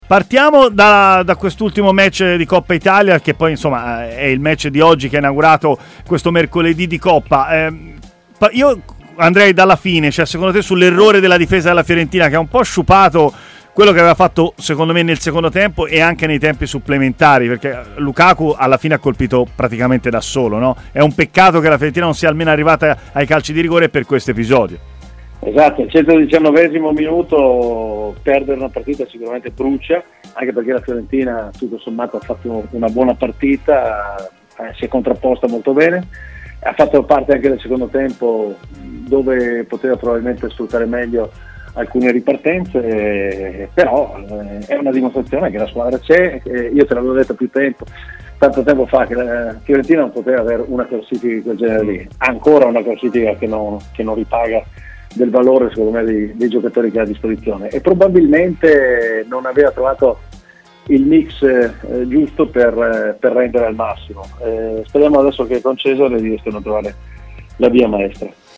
Gianni De Biasi, ct dell'Azerbaigian, ha parlato a Stadio Aperto, trasmissione di TMW Radio.